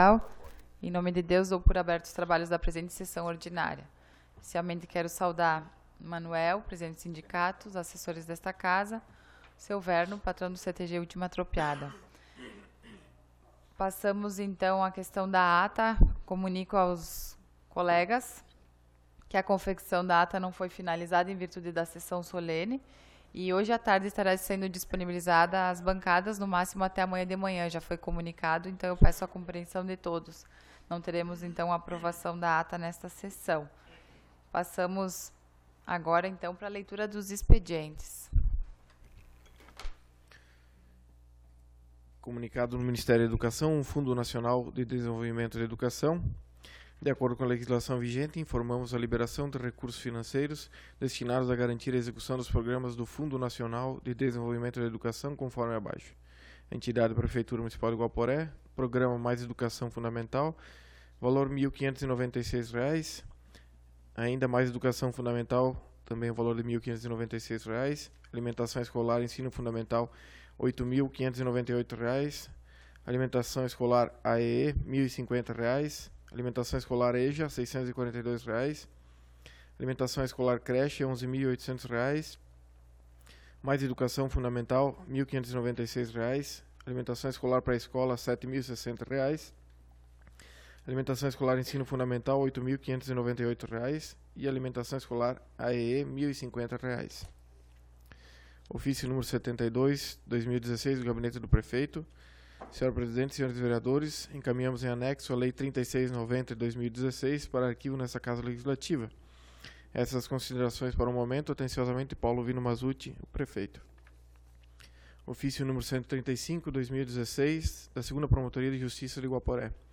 Sessão Ordinária do dia 14 de Março de 2016